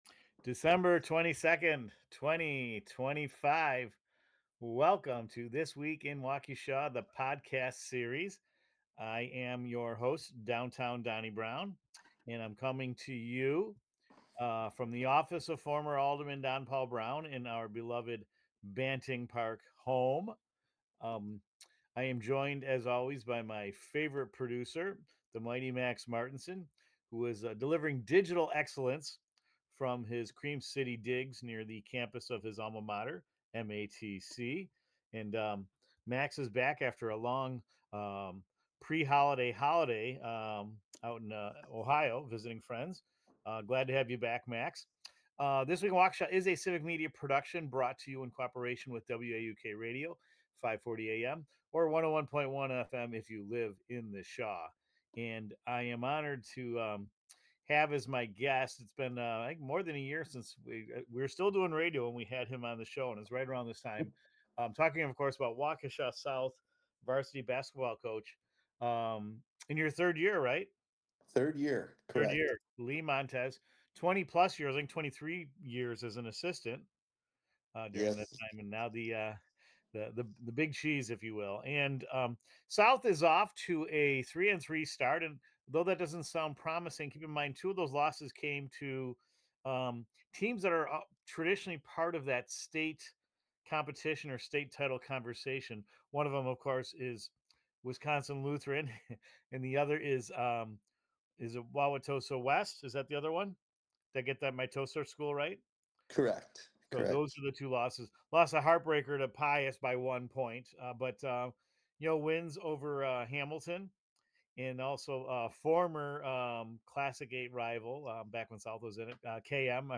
Broadcasting from the office of former Alderman Don Browne in the Banting Park neighborhood